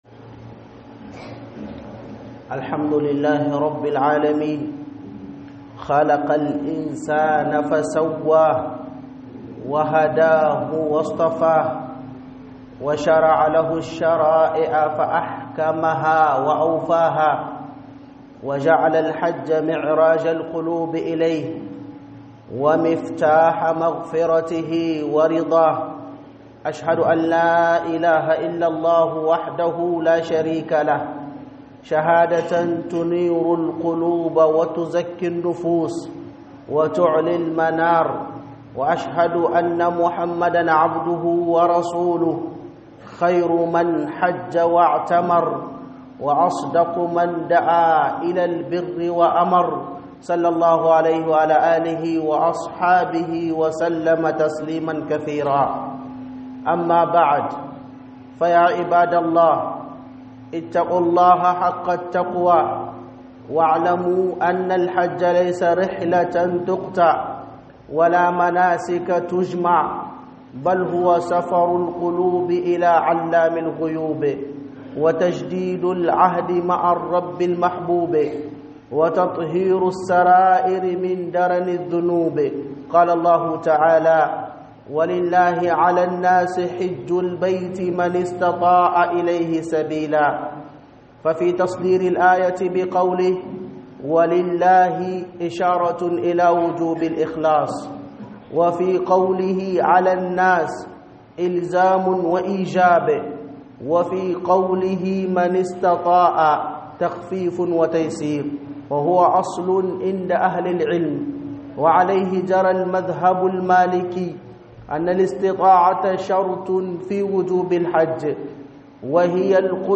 Hudubah